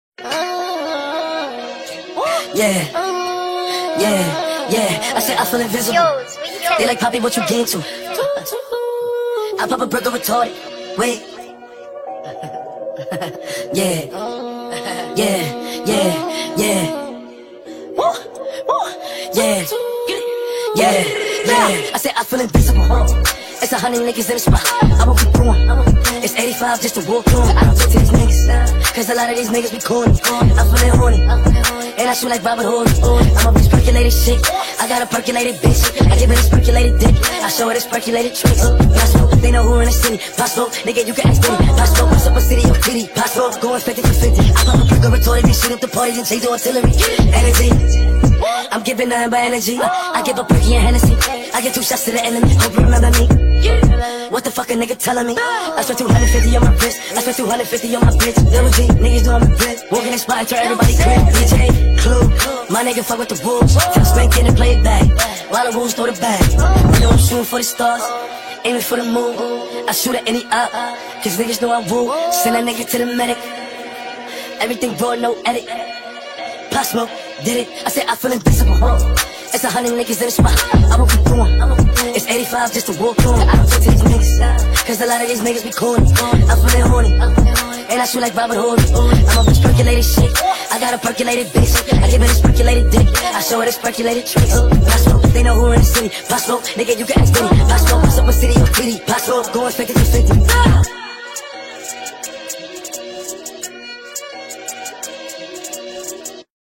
با صدای بچه